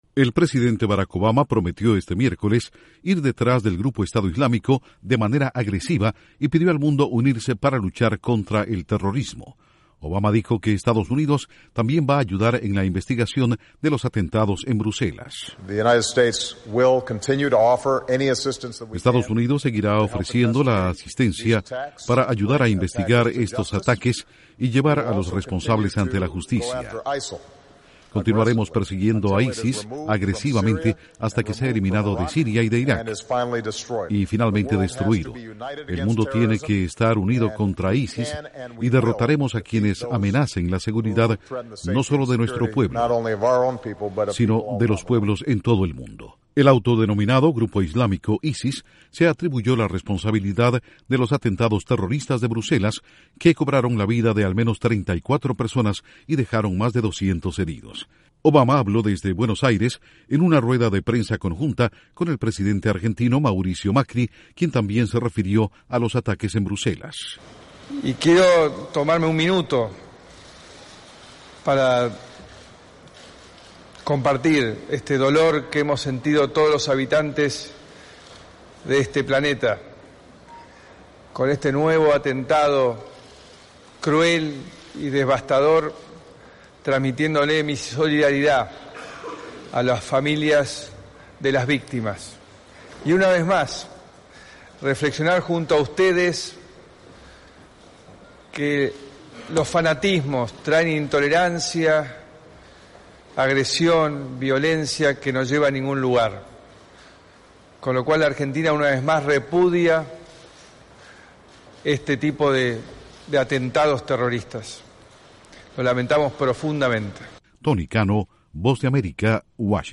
Obama lanza fuerte advertencia a ISIS durante una conferencia de prensa en Argentina junto a su homólogo Mauricio Macri.